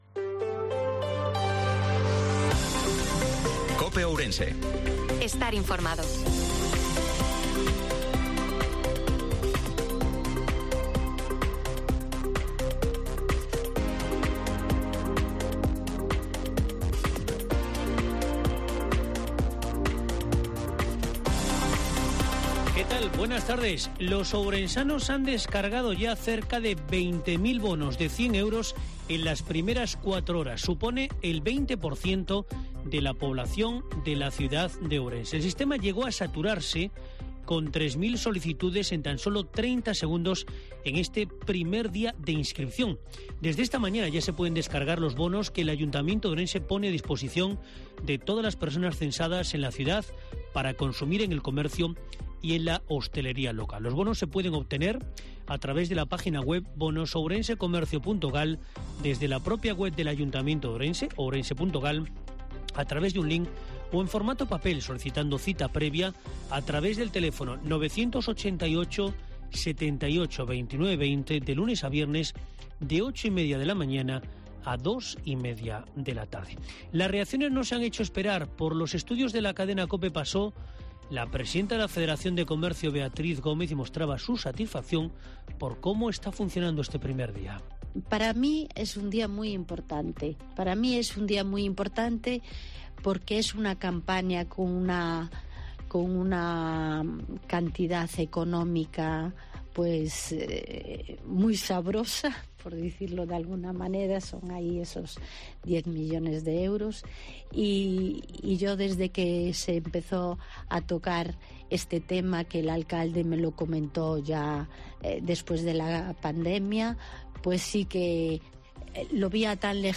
INFORMATIVO MEDIODIA COPE OURENSE-10/03/2023